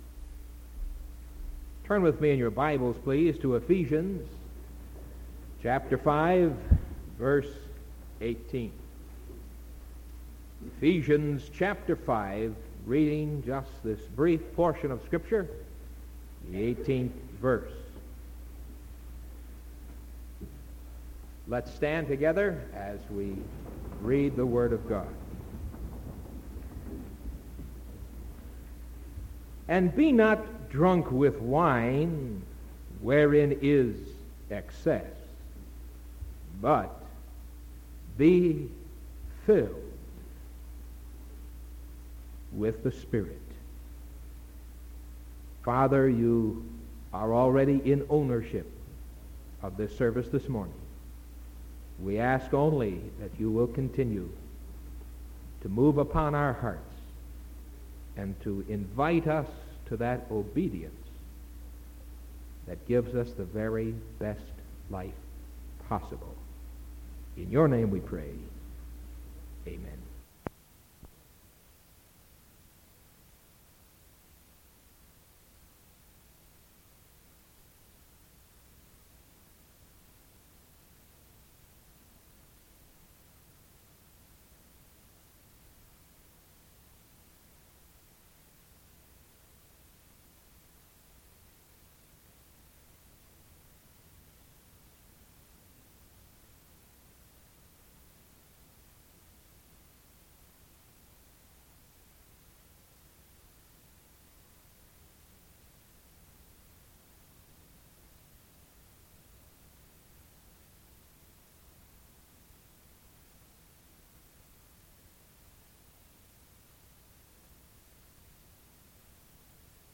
Sermon from May 5th 1974 AM